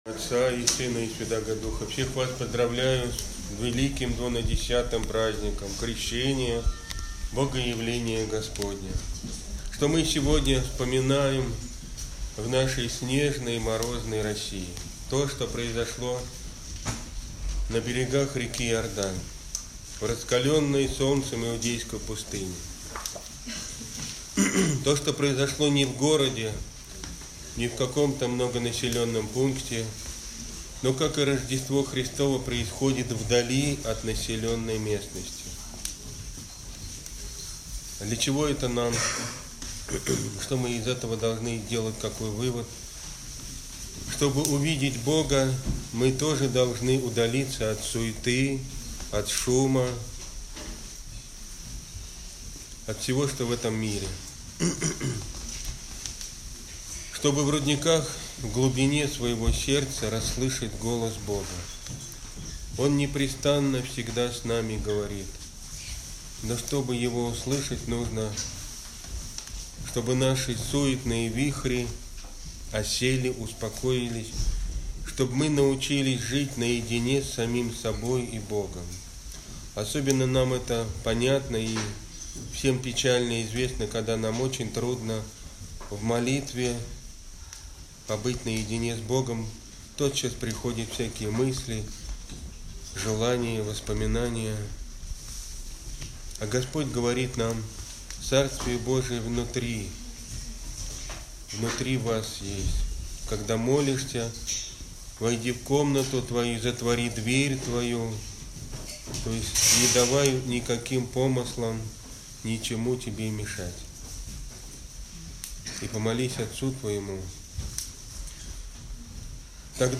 Проповедь
Аудиопроповеди